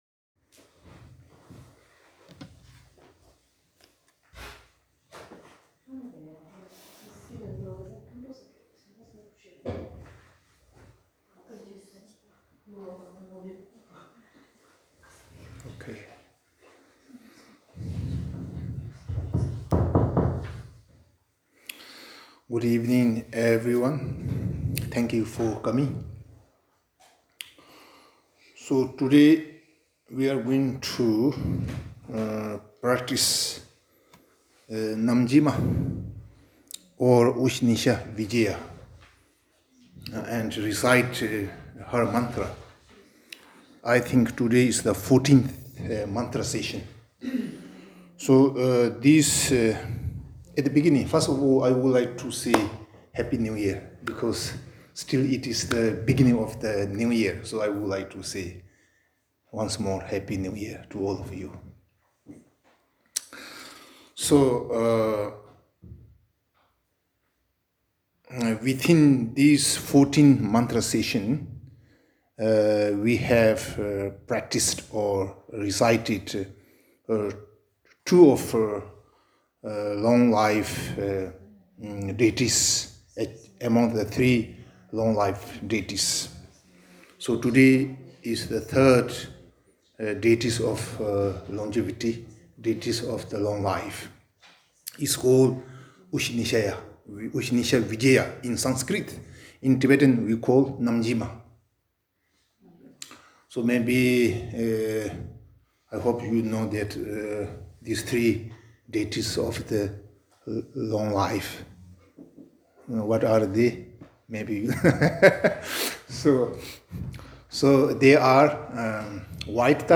14. mantrování bylo věnované obětem a ukončení ničivých požárů v Austrálii. Recitovali jsme mantru Namgjalmy a Guru Rinpočheho.
Za lednového úplňku v pátek 10. 1. jsme se počtrnácté sešli při recitaci manter nejen za dlouhý život Jeho Svatosti dalajlámy, ale tentokrát také aktuální tragédii v Austrálii.